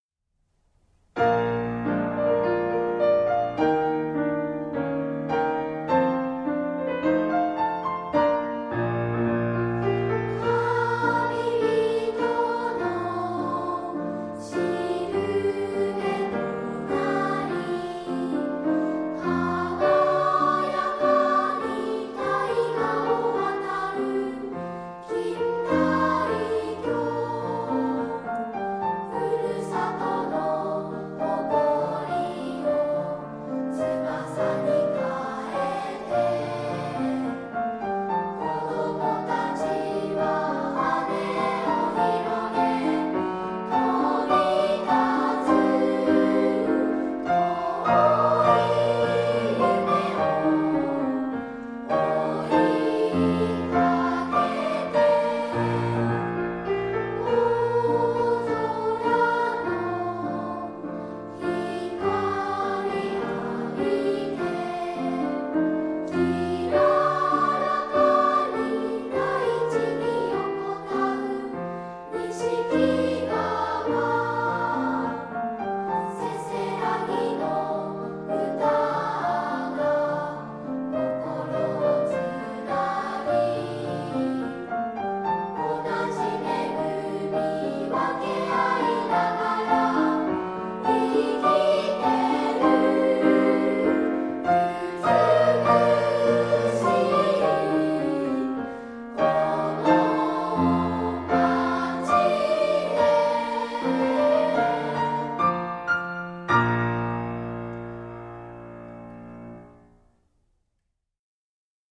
2部合唱